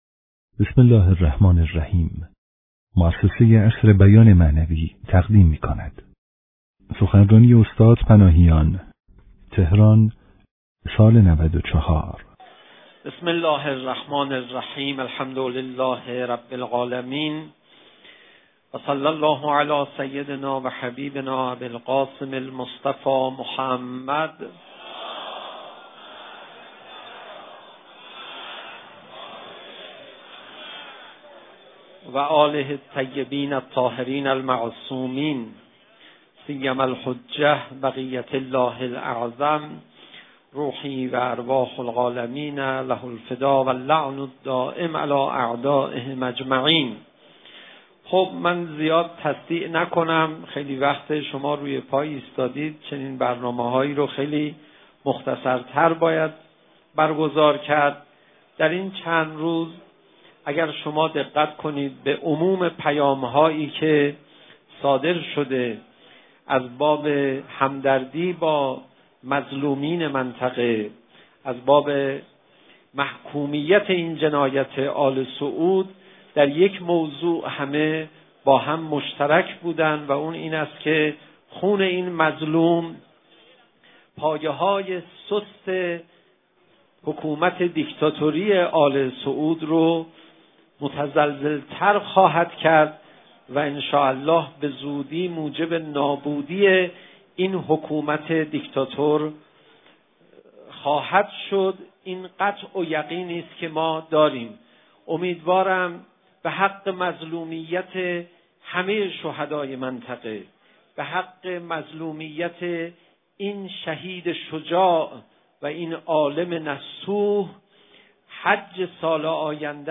در تجمع بزرگ مردم تهران در محکومیت اعدام شهید آیت‌الله نمر که در میدان امام حسین(ع) برگزار شده بود، سخنرانی کرد.
مکان: میدان امام حسین(ع)